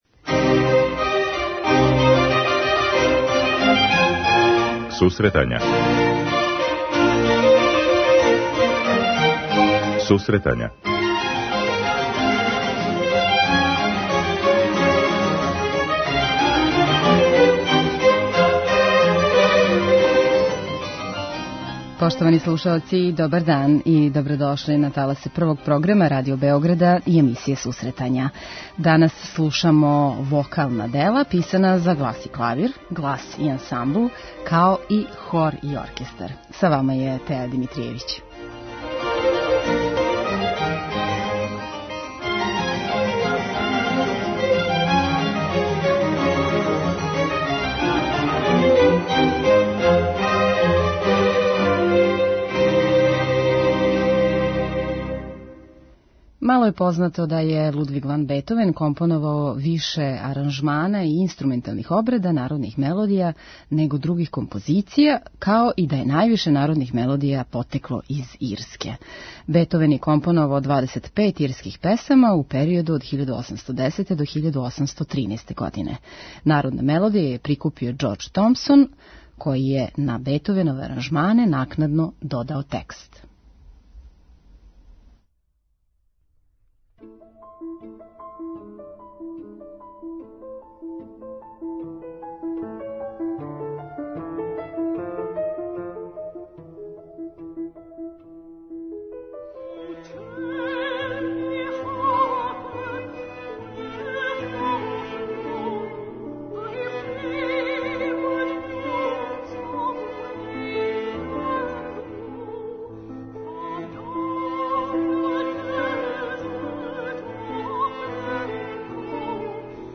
У данашњој емисији слушамо вокална дела писана за глас и клавир, глас и ансамбл и хор и оркестар као и композиторе, Бетовена, Рахмањинова, Јоханеса Брамса, Чајковског, Пуленка и Брукнера.